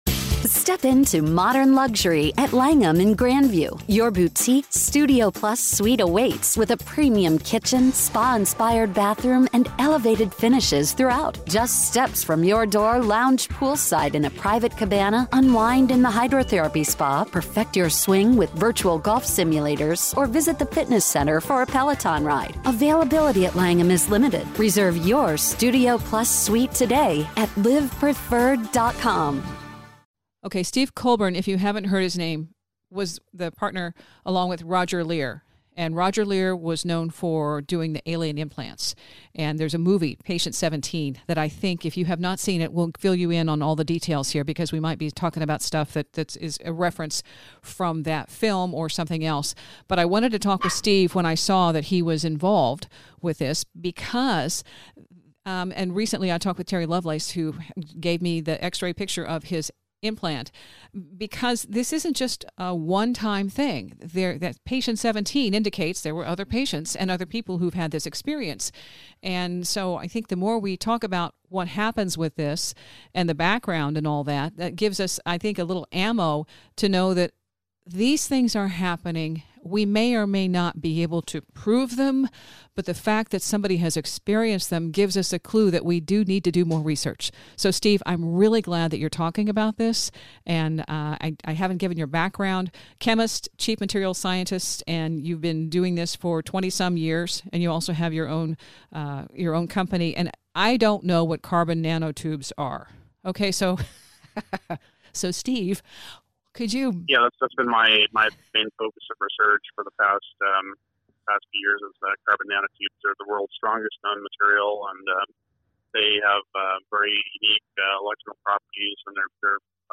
In the longer interview we talk about the paranormal events accompanying UFO activity.